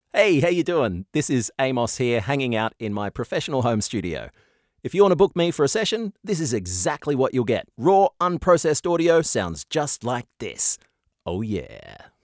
Canadian/Australian voice actor.
RAW studio Sample